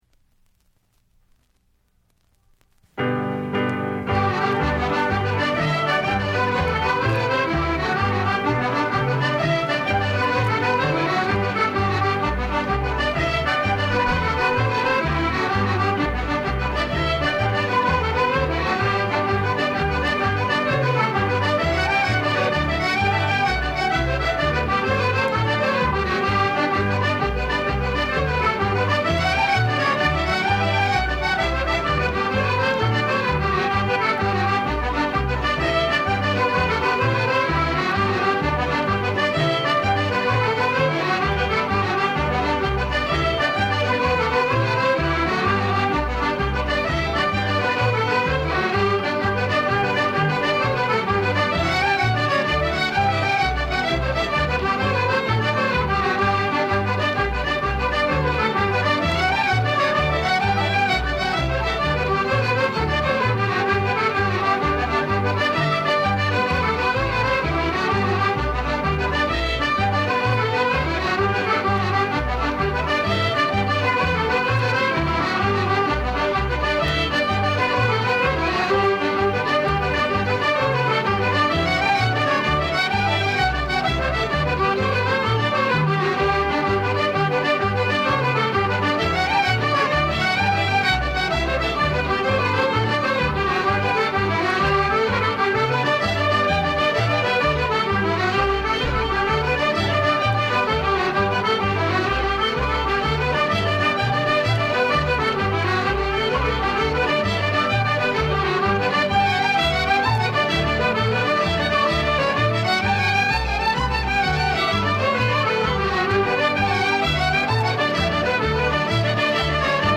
piano
string bass
fiddle
flute
jigs